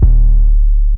REDD 808 (23).wav